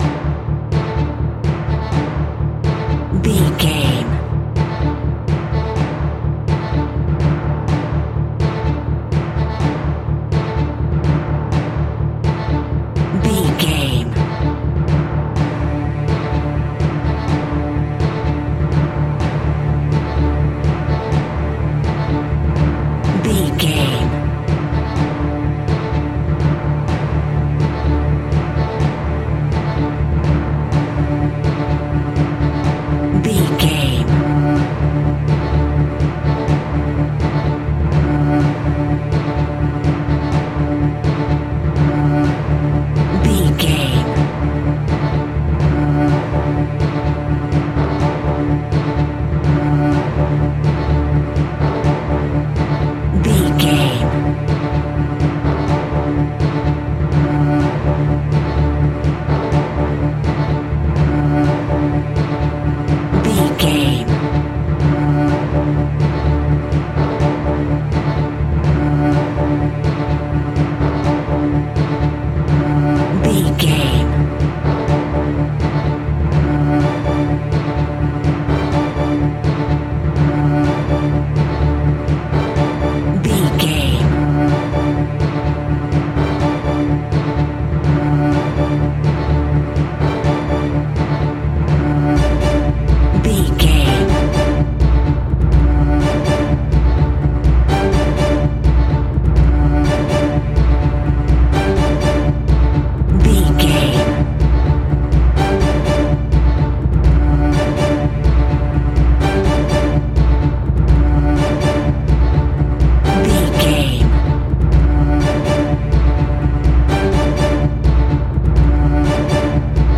Dramatic Build Up.
In-crescendo
Aeolian/Minor
Fast
tension
ominous
dark
suspense
eerie
strings
synth
pads